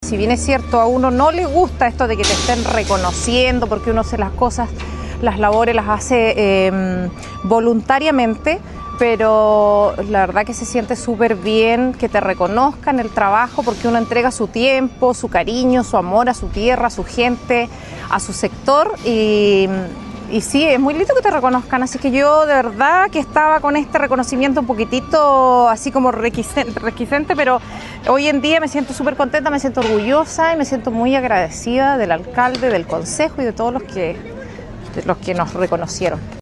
Cientos de asistentes llegaron el viernes último al frontis de la Municipalidad de Pucón, en donde prácticamente todas las fuerzas vivas de la urbe lacustre se reunieron para festejar los 143 años de existencia de esta reconocida urbe turística en un desfile cívico, del que fueron parte más de 40 organizaciones.